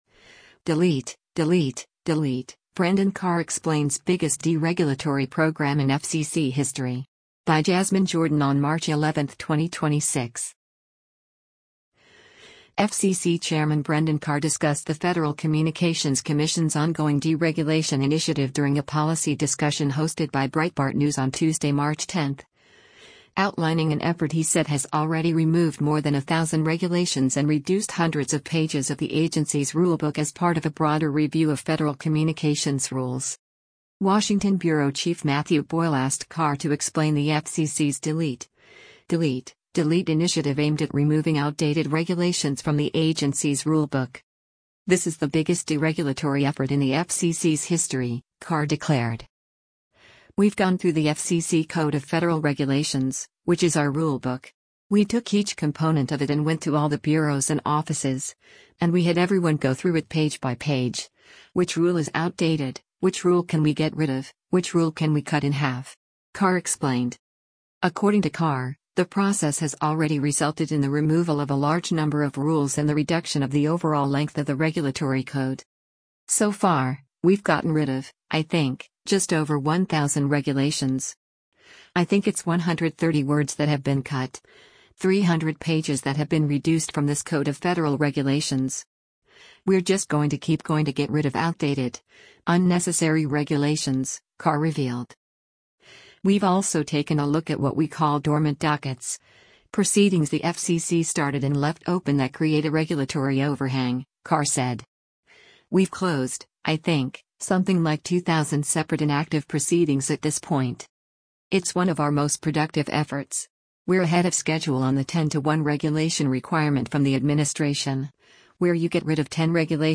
FCC Chairman Brendan Carr discussed the Federal Communications Commission’s ongoing deregulation initiative during a policy discussion hosted by Breitbart News on Tuesday, March 10, outlining an effort he said has already removed more than a thousand regulations and reduced hundreds of pages of the agency’s rulebook as part of a broader review of federal communications rules.